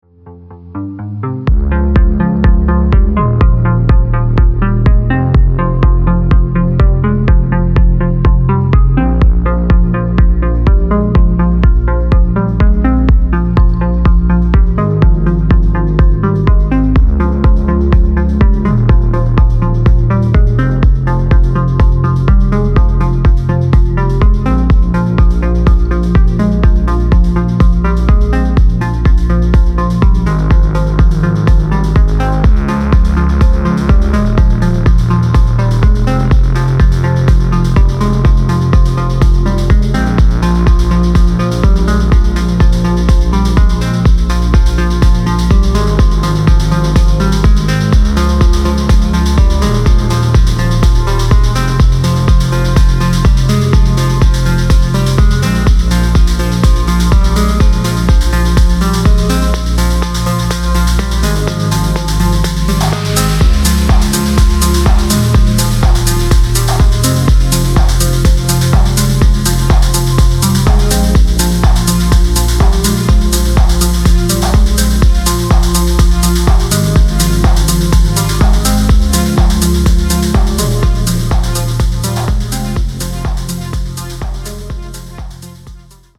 • Качество: 320, Stereo
ритмичные
Electronic
спокойные
без слов
расслабляющие
house
relax